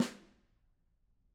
Snare2-HitSN_v5_rr1_Sum.wav